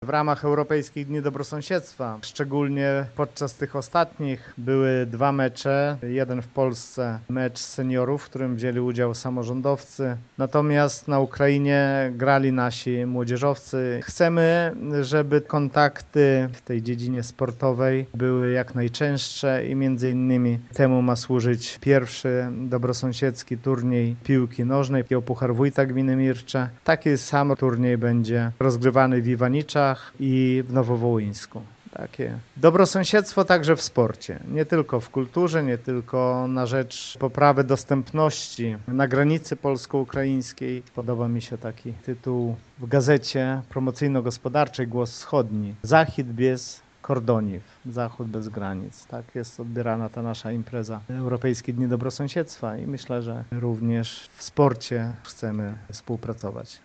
- Taki turniej odbędzie się po raz pierwszy, ale wspólne zawody sportowe już organizowaliśmy - przypomina wójt Gminy Mircze Lech Szopiński: